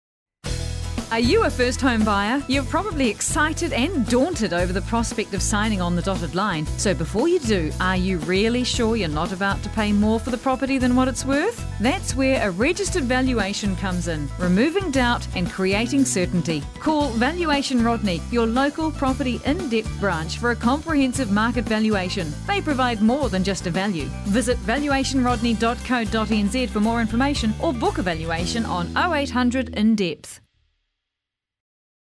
Radio Advertising